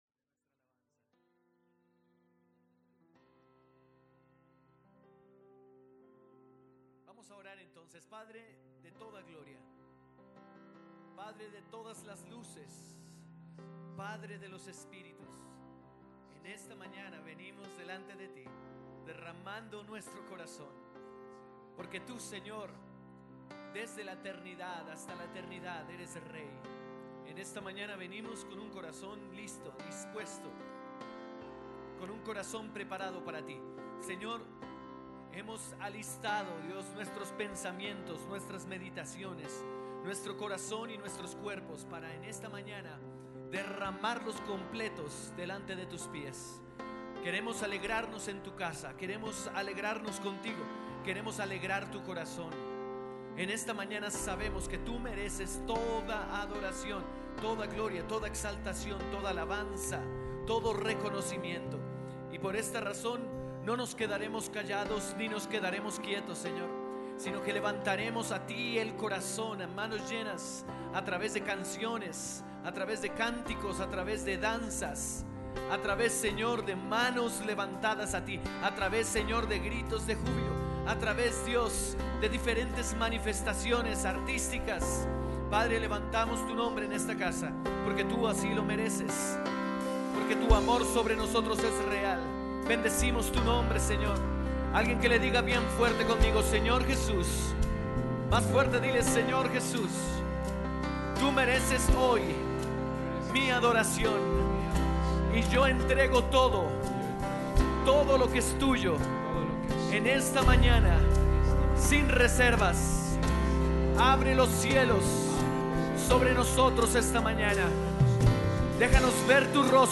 Lección 1: Alabanza febrero 22, 2015.